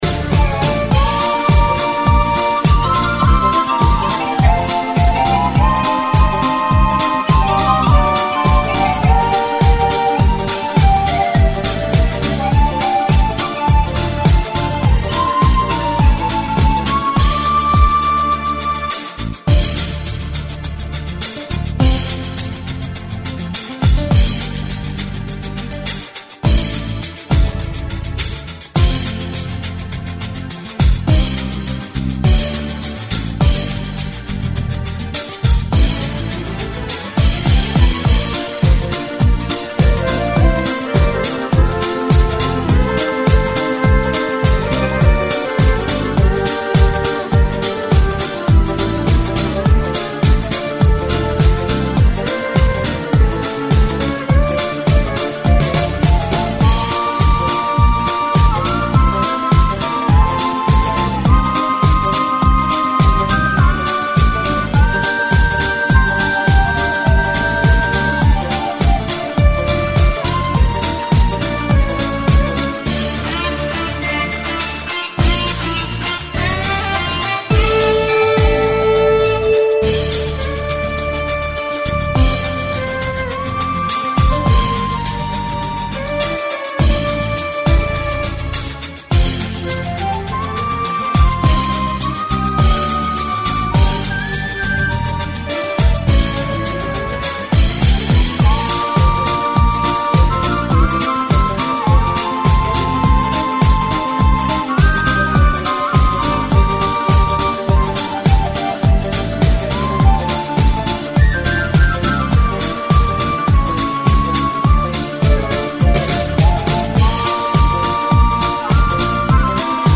Українська популярна музика.